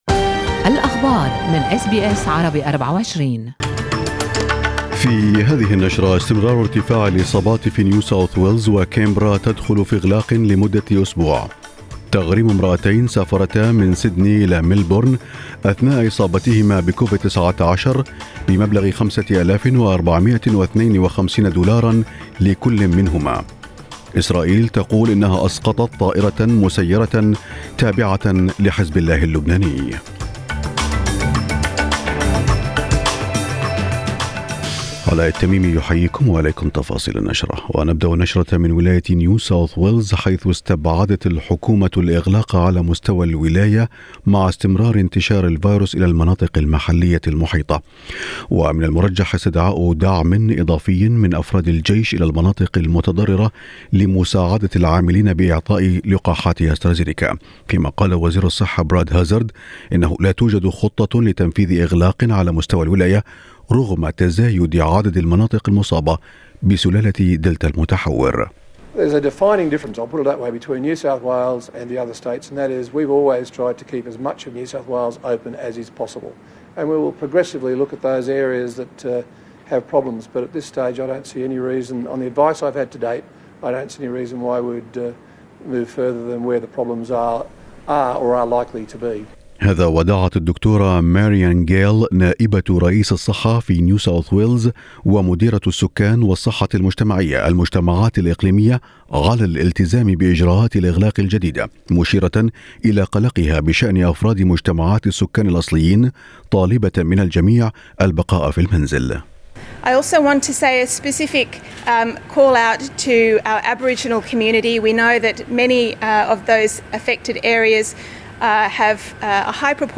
نشرةأخبار الصباح 13/8/2021
يمكنكم الاستماع الى النشرة الاخبارية كاملة بالضغط على التسجيل الصوتي أعلاه.